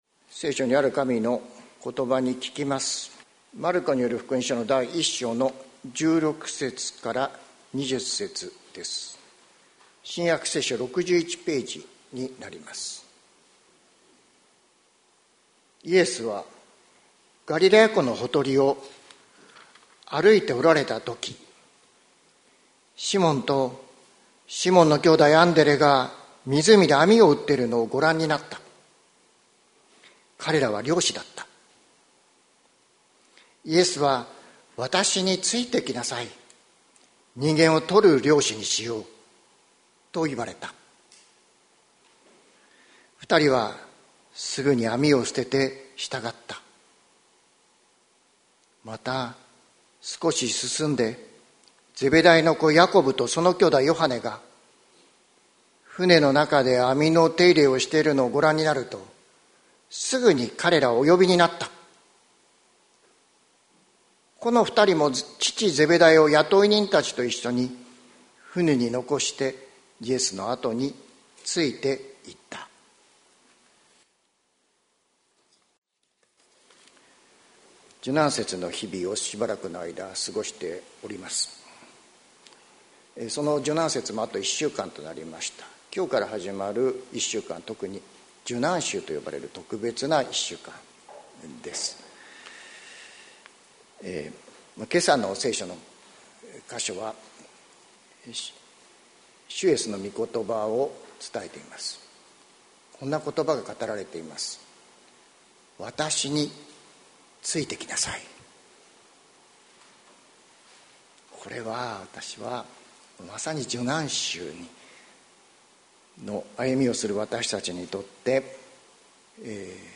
2025年04月13日朝の礼拝「主イエスの背を見つめて」関キリスト教会
説教アーカイブ。